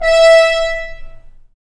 Index of /90_sSampleCDs/Roland L-CDX-03 Disk 2/BRS_F.Horns FX+/BRS_FHns Mutes